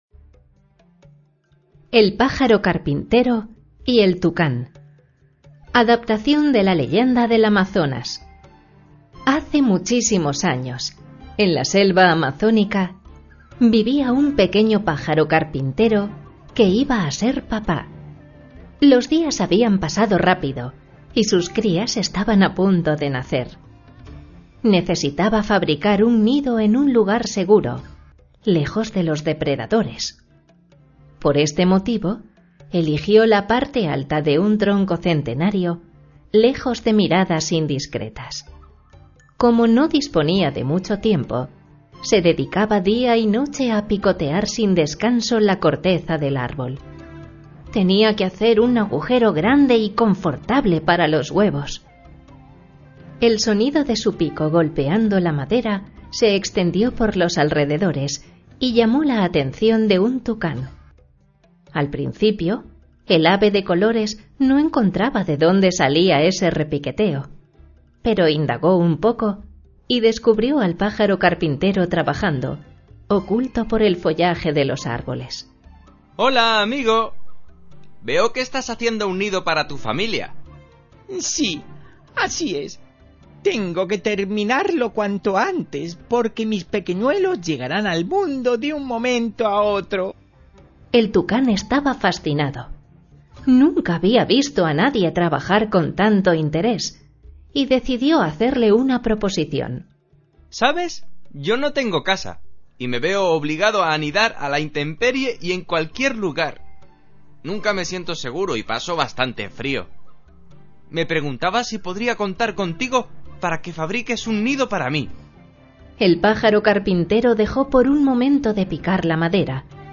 AUDIOCUENTOS PARA LOS MAS PEQUEÑOS